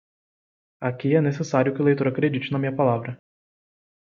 Read more Adj Noun Noun Frequency C2 Pronounced as (IPA) /lejˈtoʁ/ Etymology Semi-learned borrowing from Latin lēctōrem (“reader”).